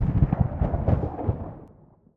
thunderclap.ogg